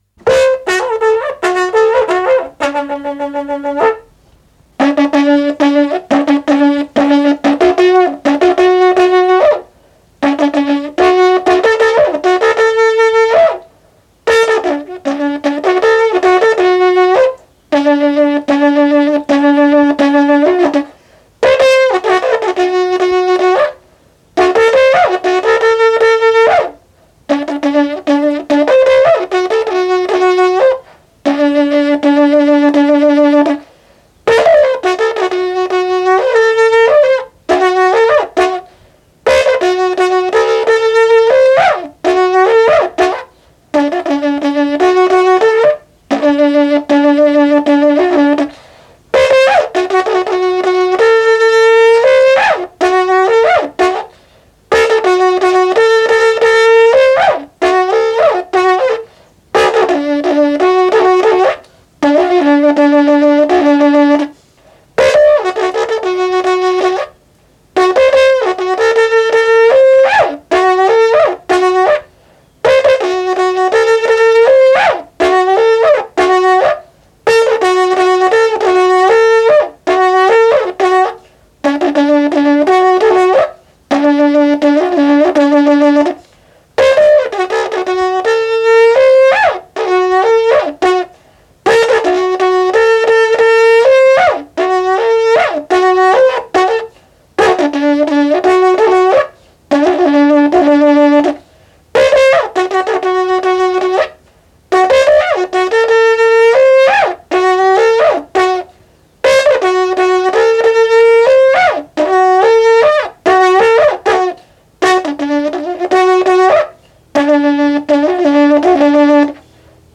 Música mapuche (Comunidad Cerro Loncoche, Metrenco)
Música vocal
Tradición oral